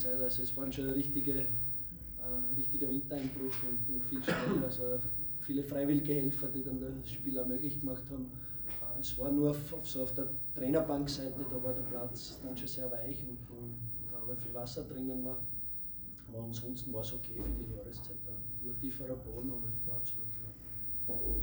Cheftrainer Christian Ilzer bei der Pressekonferenz nach dem Unentschieden in Linz.